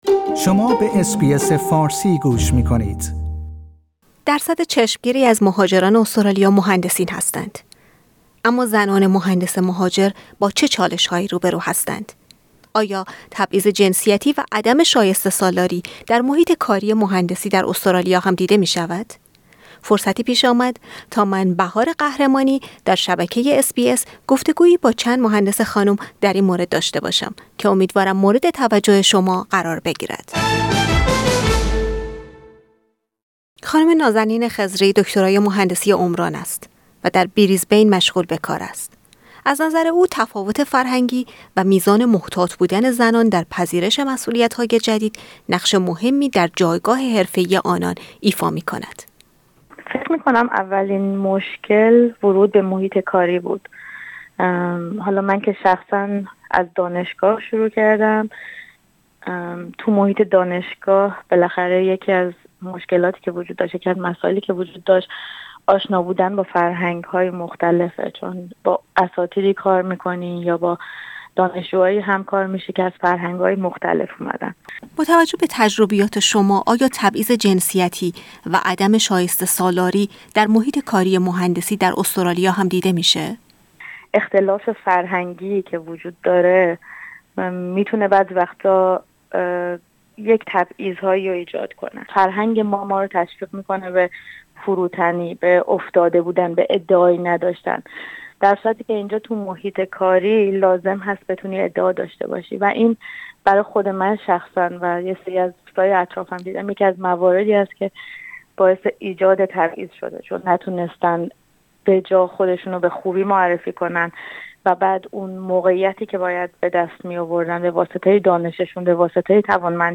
آيا تبعيض جنسيتي و عدم شايسته سالاري در محيط كار مهندسي در استراليا هم ديده مي شود؟ فرصتي پيش آمد تا گفتگويي با چند خانم مهندس در اين مورد داشته باشیم.